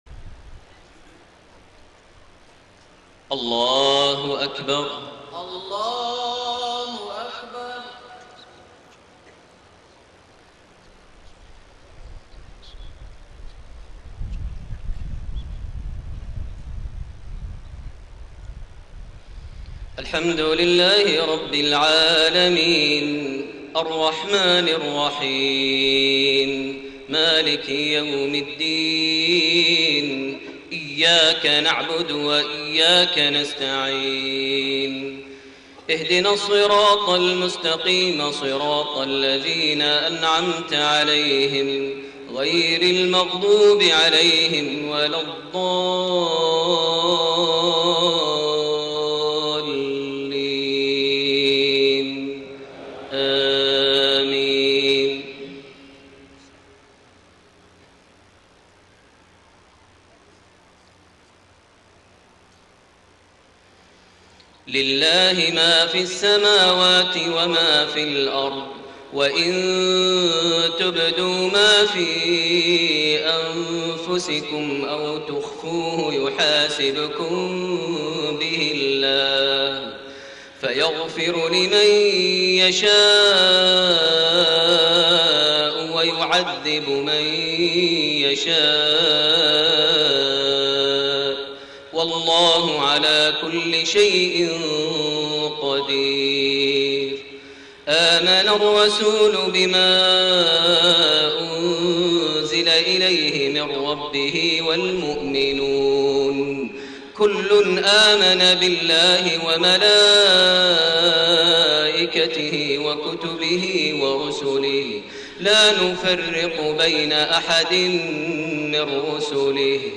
صلاة المغرب7-7-1431من سورة البقرة 284-286 > 1431 هـ > الفروض - تلاوات ماهر المعيقلي